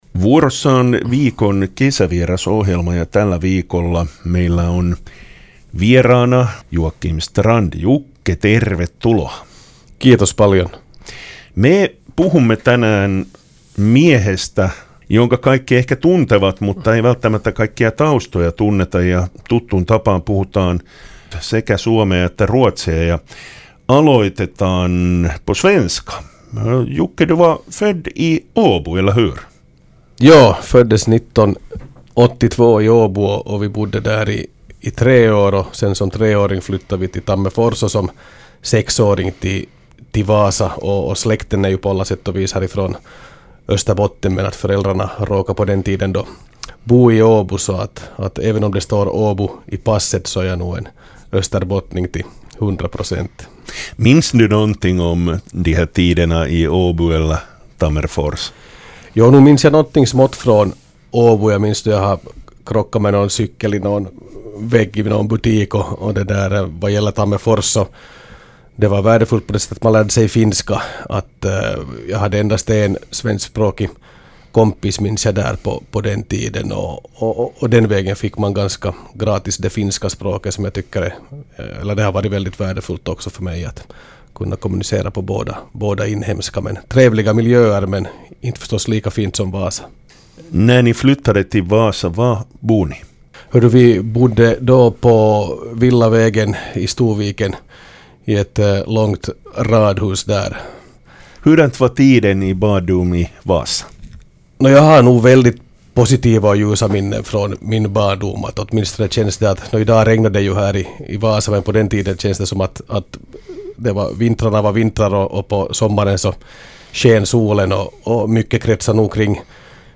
Viikon kesävieraana oli vaasalainen Joakim Strand, joka kertoo tiestään lapsuudesta ministerin tehtäviin.